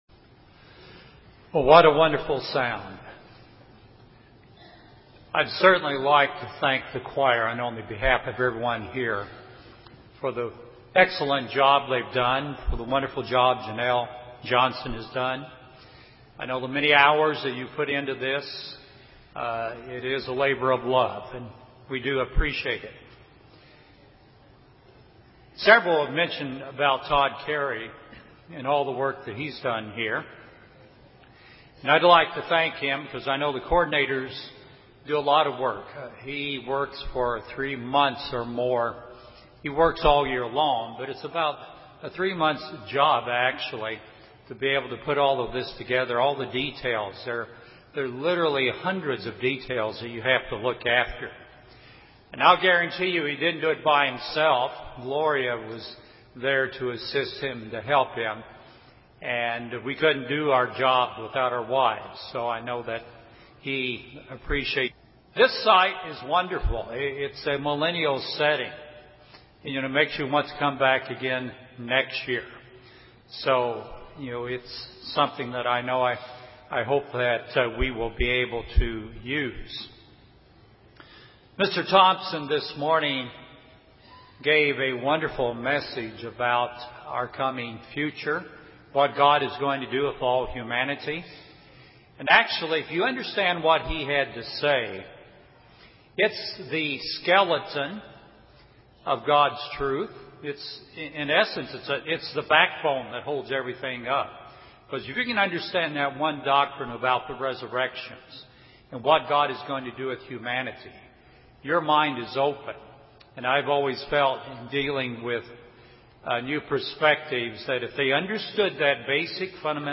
See The Future - From Dust to Divine (FOT LGD PM) UCG Sermon Transcript This transcript was generated by AI and may contain errors.